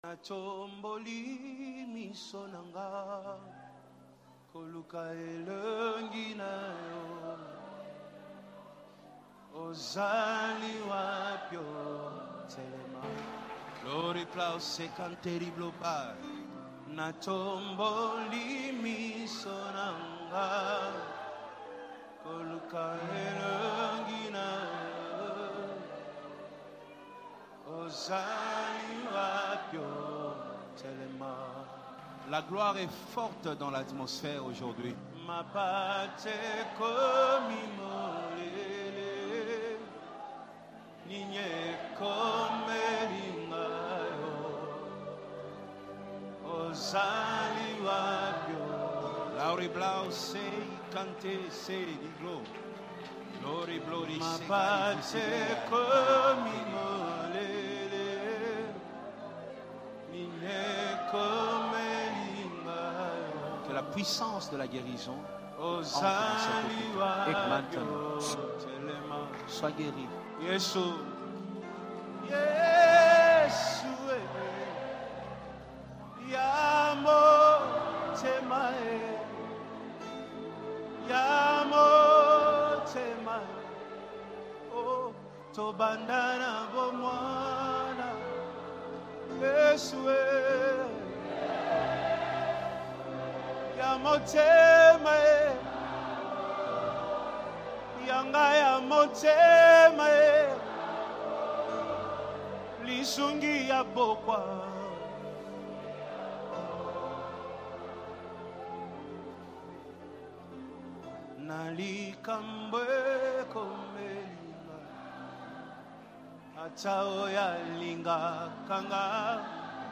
🔥 PRAYER DETAILS: